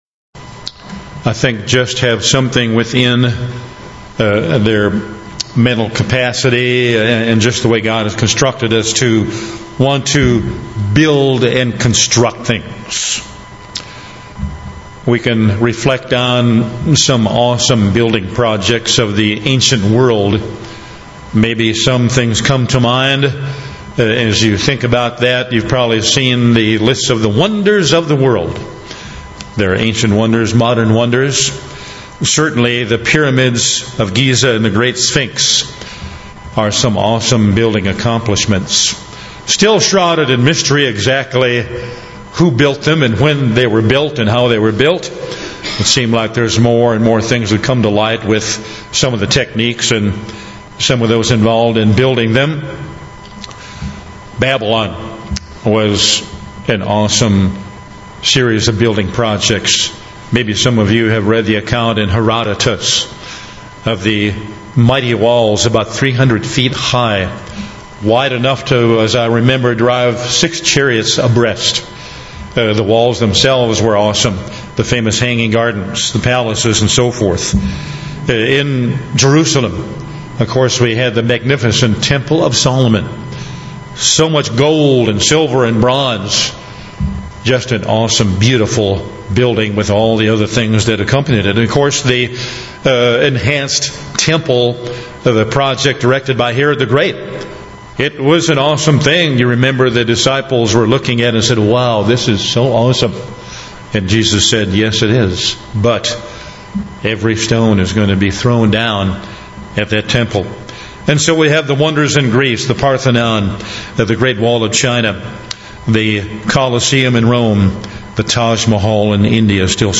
Given in Bismarck, ND
UCG Sermon Studying the bible?